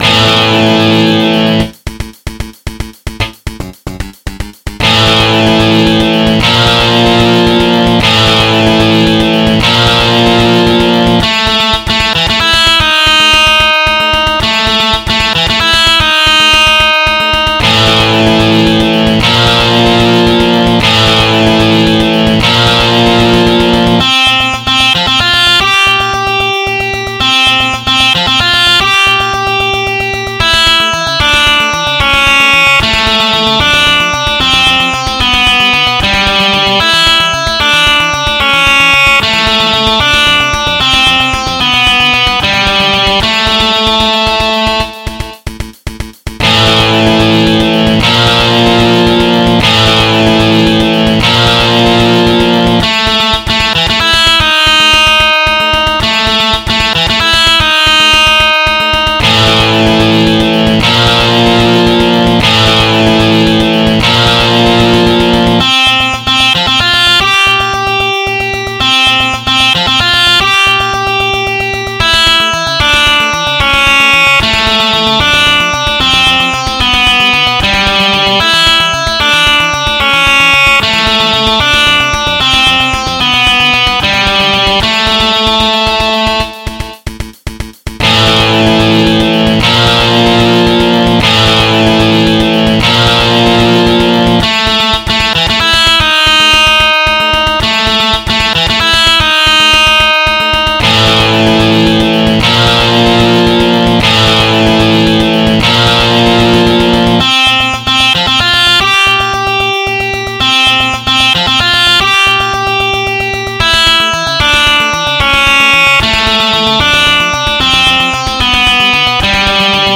MIDI 41.06 KB MP3 (Converted) 2.67 MB MIDI-XML Sheet Music